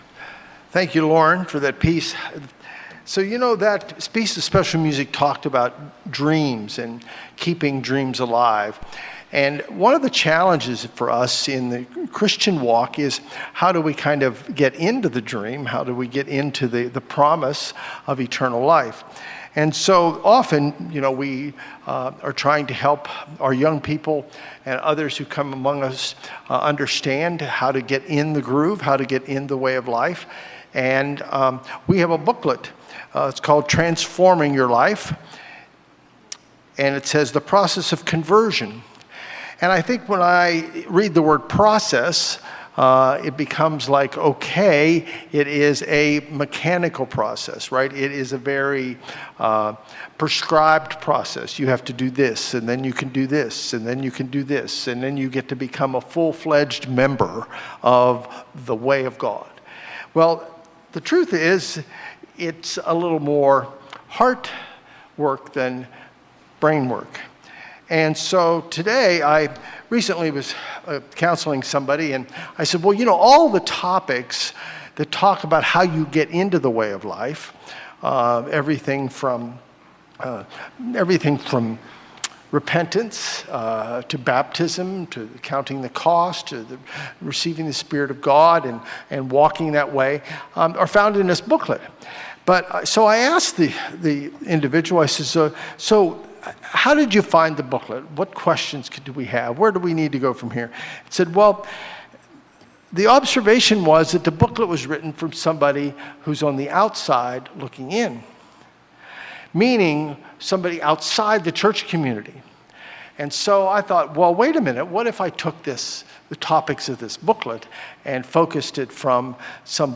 Listen as these issues are thoughtfully considered in this message.